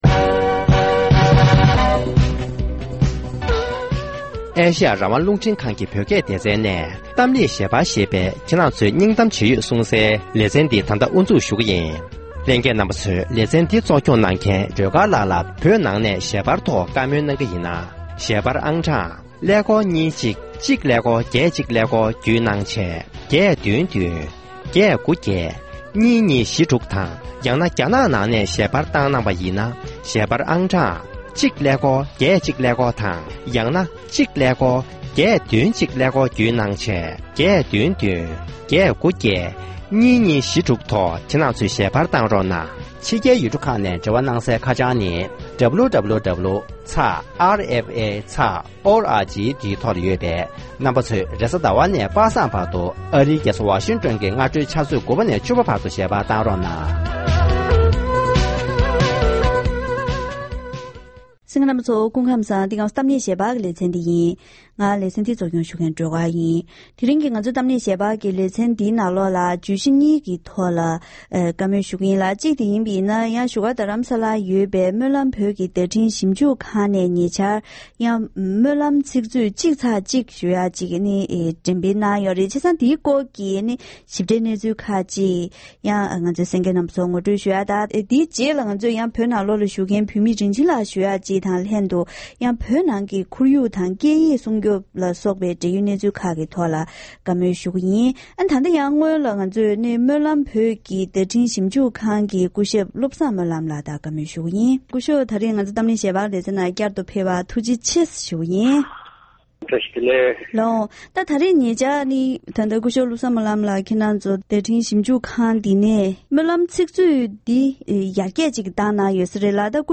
༄༅༎དེ་རིང་གི་གཏམ་གླེང་ཞལ་པར་ལེ་ཚན་ནང་ཉེ་ཆར་སྨོན་ལམ་བོད་ཀྱི་བརྡ་འཕྲིན་ཞིབ་འཇུག་ཁང་ནས་སྨོན་ལམ་ཚིག་མཛོད་ཐོན་གསར་པ་ཞིག་འགྲེམས་སྤེལ་གནང་སྐོར་དང་།